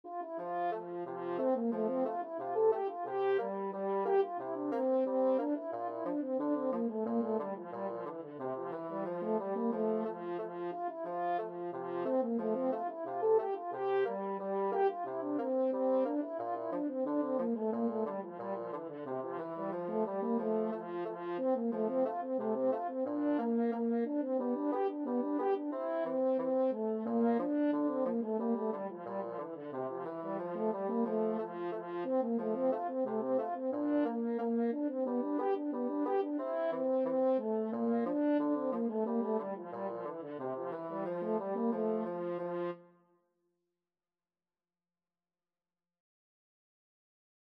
French HornBassoon
2/2 (View more 2/2 Music)
F major (Sounding Pitch) (View more F major Music for French Horn-Bassoon Duet )
French Horn-Bassoon Duet  (View more Intermediate French Horn-Bassoon Duet Music)
Traditional (View more Traditional French Horn-Bassoon Duet Music)